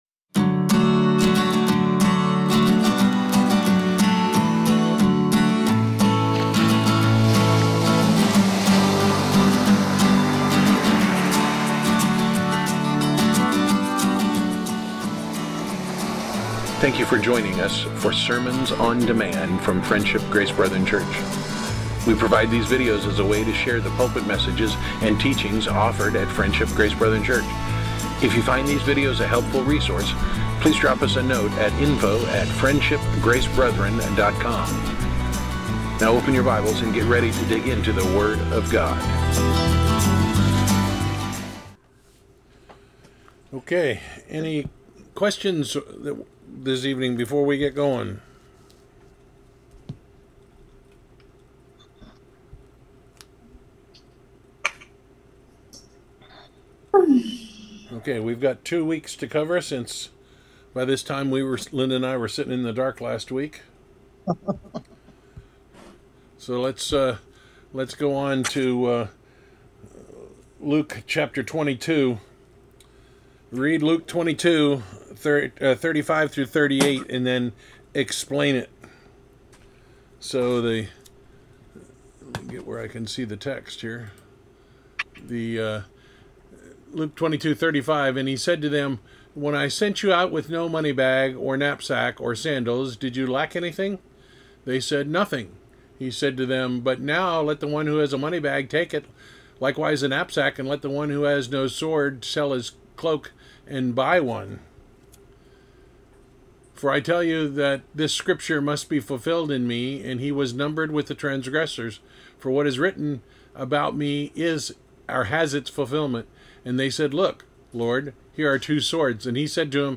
Series: Weekly Bible Discussion